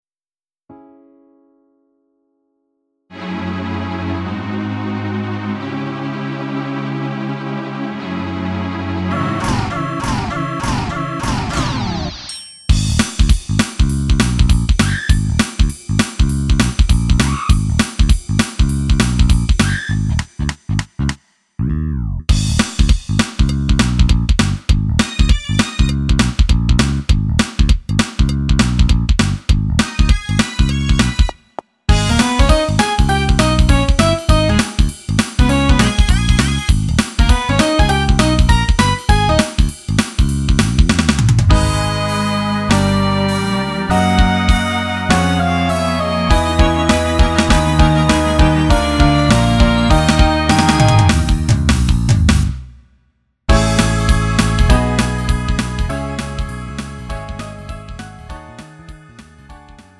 음정 -1키 3:31
장르 가요 구분 Lite MR
Lite MR은 저렴한 가격에 간단한 연습이나 취미용으로 활용할 수 있는 가벼운 반주입니다.